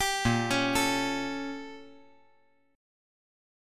BbmM13 Chord
Listen to BbmM13 strummed